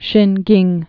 (shĭngĭng)